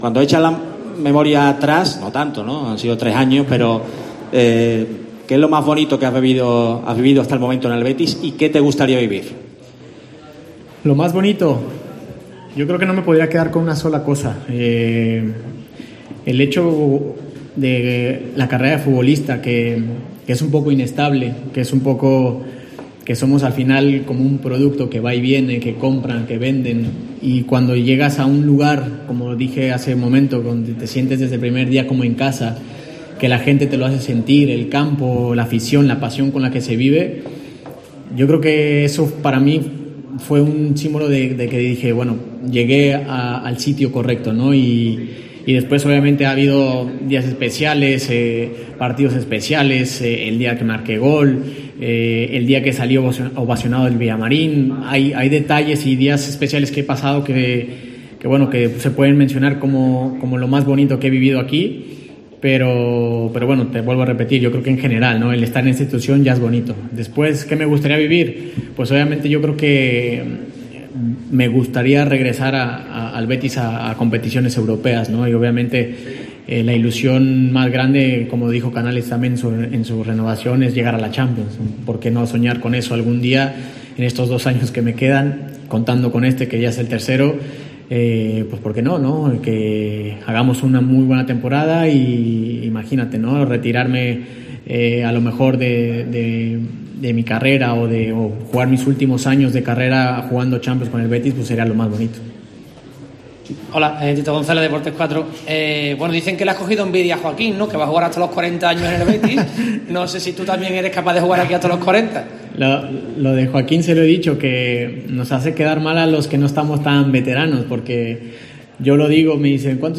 Andrés Guardado atendió a los medios en el acto de su renovación con el Betis hasta 2022
En esta página puedes escuchar la interesantísima rueda de prensa del jugador.